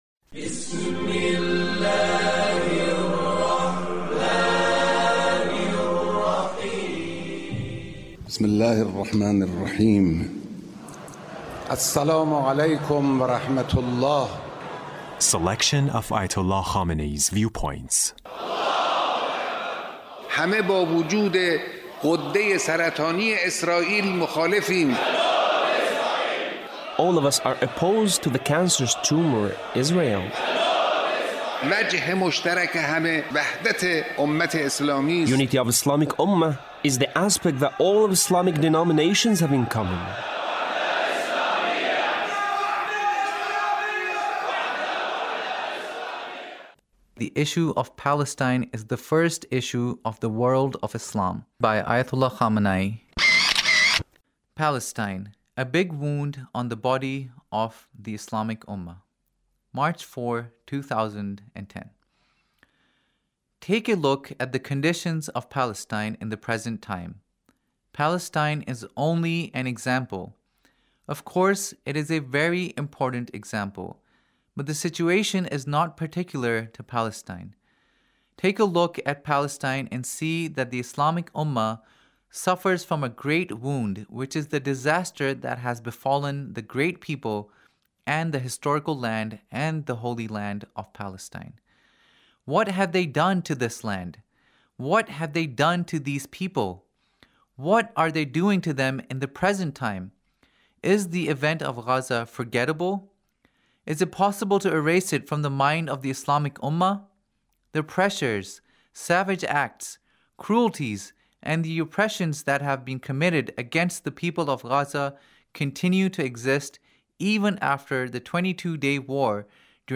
Leader's Speech (1871)